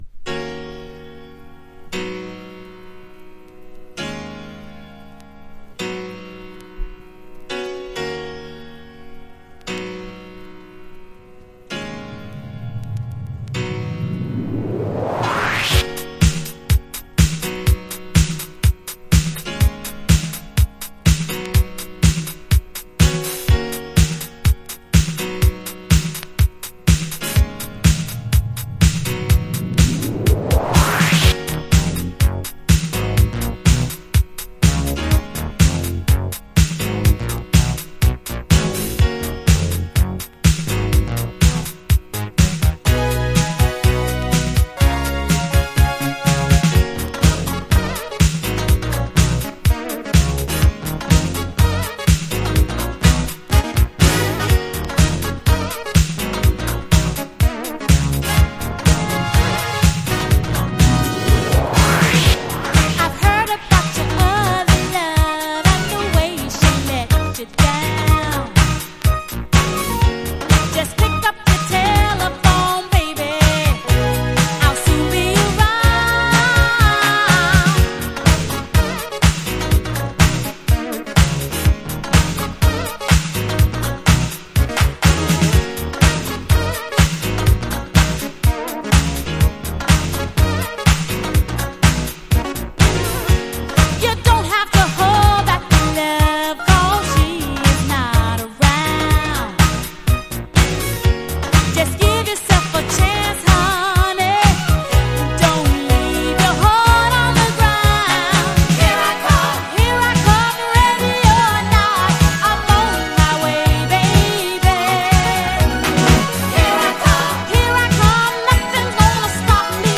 ピアノのイントロから始まるアーバンでクールなデジタル・エイティーズ・ディスコ!!
# FUNK / DEEP FUNK# DISCO